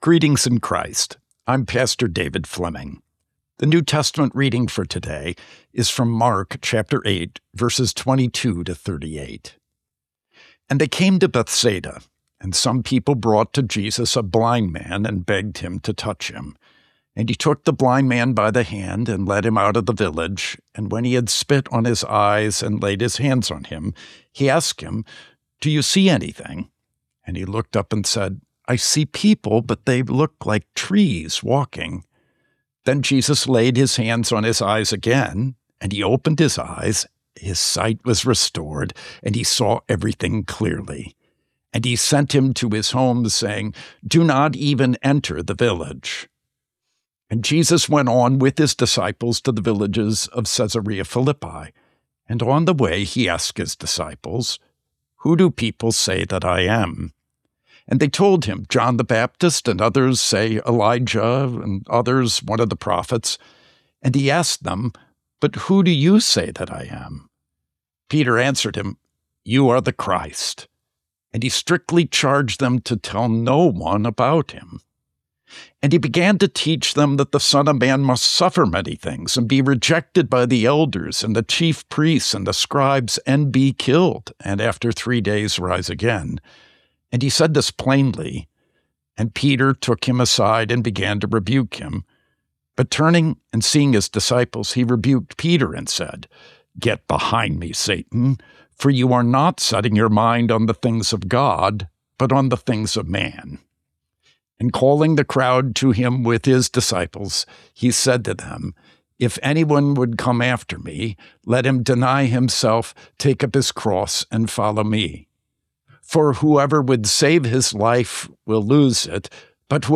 Morning Prayer Sermonette: Mark 8:22-38
Hear a guest pastor give a short sermonette based on the day’s Daily Lectionary New Testament text during Morning and Evening Prayer.